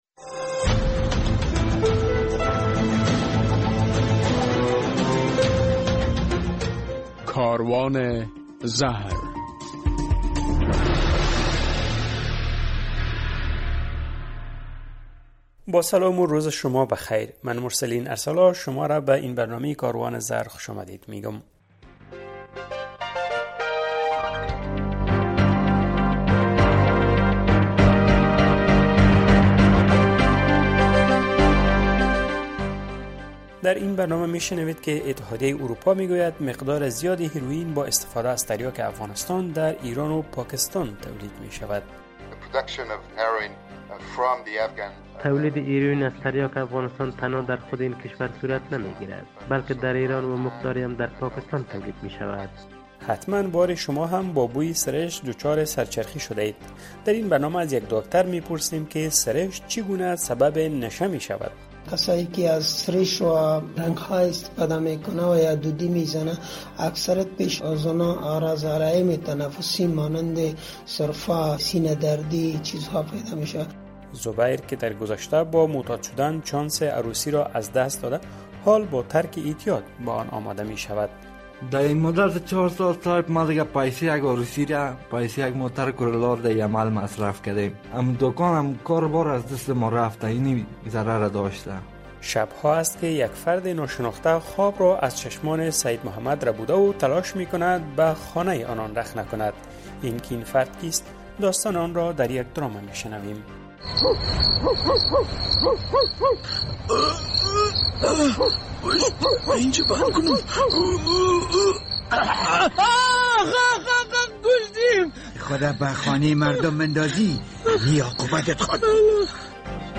در این برنامه کاروان زهر می‌شنوید که اتحادیه اروپا می‌گوید بیشترین تریاک افغانستان در کشورهای ایران و پاکستان به هیرویین مبدل می‌شود و به اروپا قاچاق می‌گردد. در مصاحبه با یک داکتر از وی پرسیدیم که بوی سریش چگونه سبب اعتیاد انسان‌ها می‌شود؟